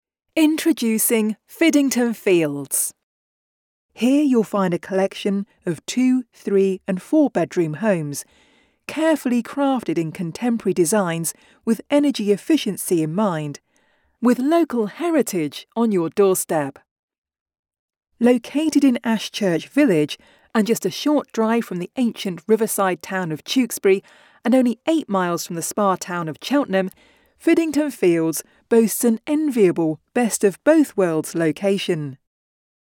Female
Assured, Authoritative, Confident, Corporate, Engaging, Friendly, Gravitas, Natural, Reassuring, Smooth, Soft, Warm, Witty, Conversational
Voice of God Awards Announcer demo.mp3